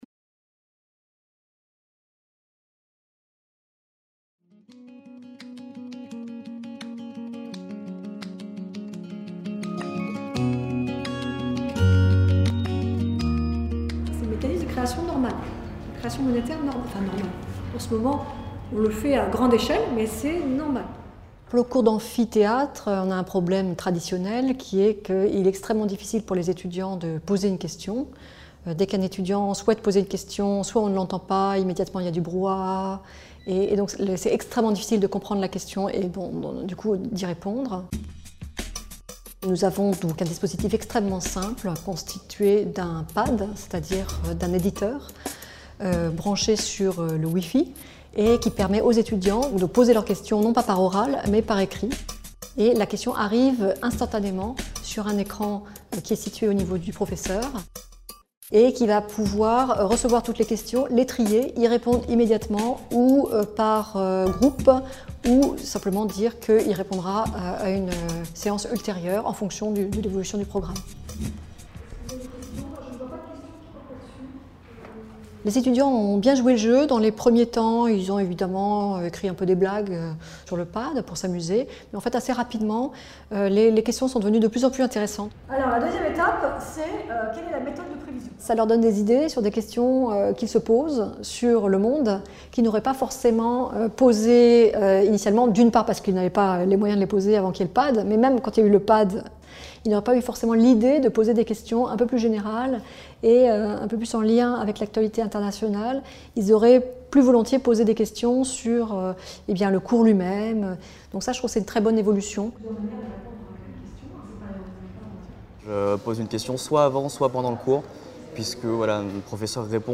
Agnès Bénassy-Quéré, enseignante en économie à l'université Paris 1, décrit dans cette vidéo comment la mise en place d’un « pad » (éditeur de texte collaboratif en ligne) permet à nouveau à ses étudiant.e.s de lui poser des questions pendant son cours en amphithéâtre. Elle prévoit des temps pour consulter les questions des étudiant.e.s en début et aux moments opportuns de son cours.